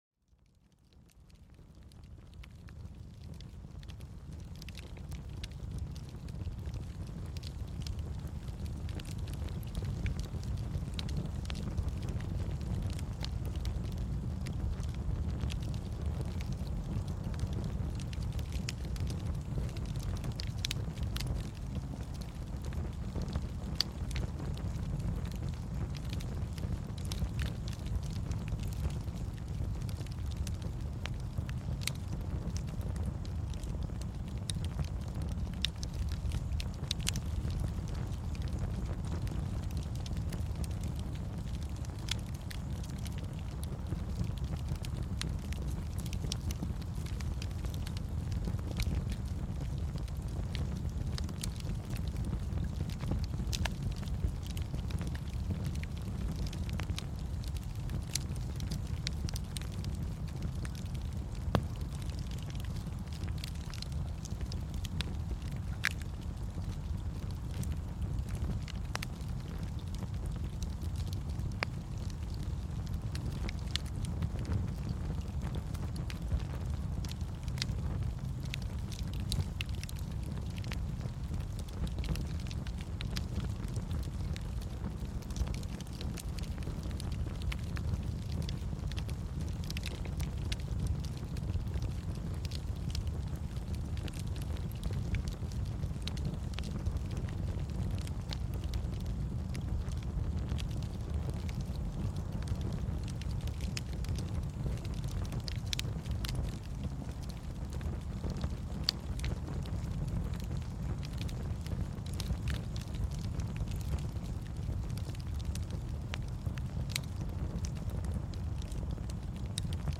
Grâce à des enregistrements de haute qualité, chaque épisode est une invitation à se déconnecter du quotidien et à se ressourcer au cœur de la nature.